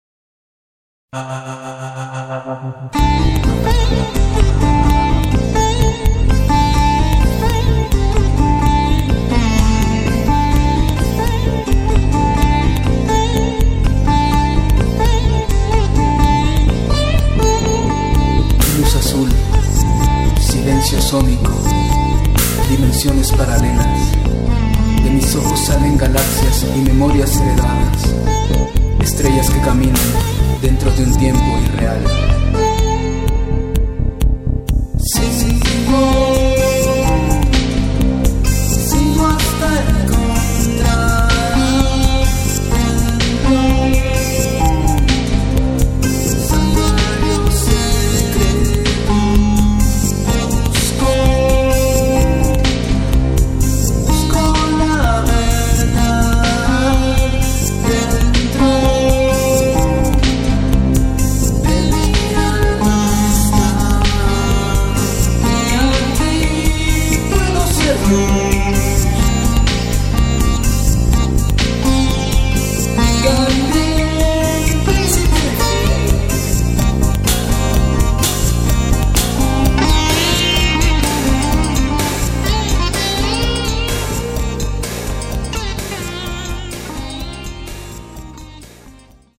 sitar, guitar, bass
percussion
piano, vocals